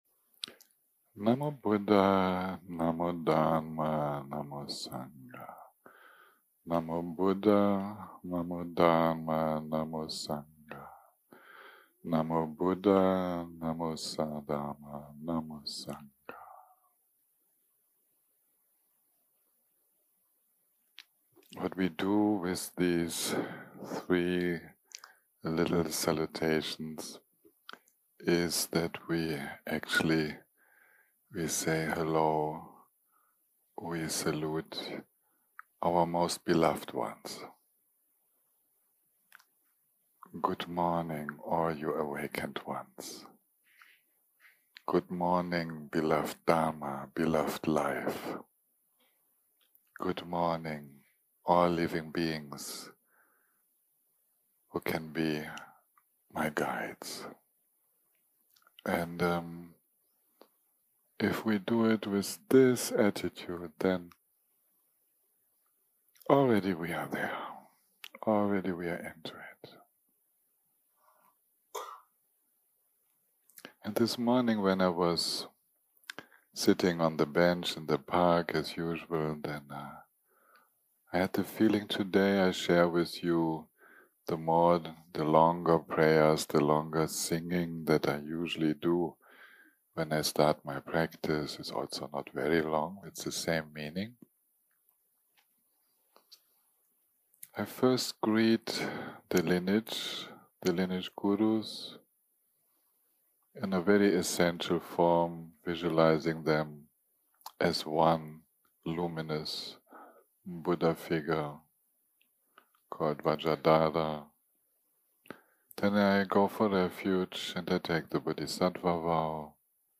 יום 8 - הקלטה 35 - בוקר - מדיטציה מונחית - Chanting + Tong Len 4 - Amazing beauty
Guided meditation